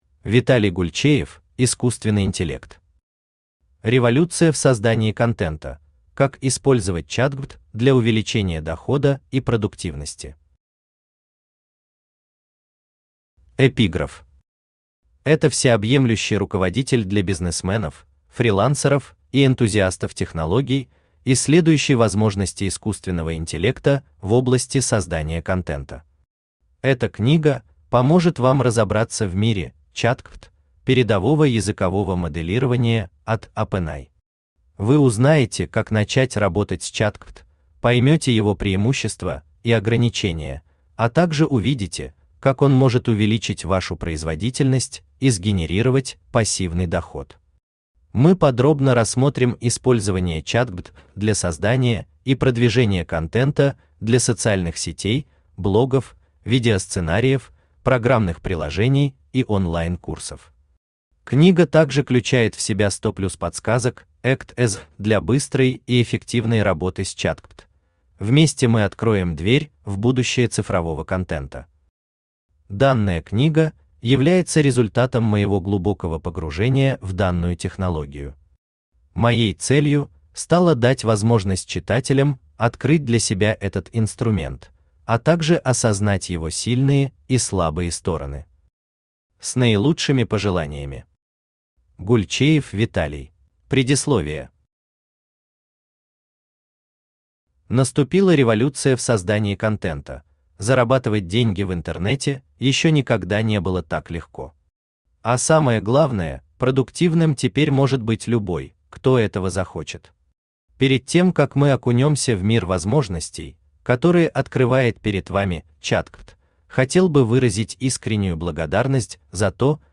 Аудиокнига Революция в создании контента: как использовать ChatGPT для увеличения дохода и продуктивности | Библиотека аудиокниг
Aудиокнига Революция в создании контента: как использовать ChatGPT для увеличения дохода и продуктивности Автор Виталий Александрович Гульчеев Читает аудиокнигу Авточтец ЛитРес.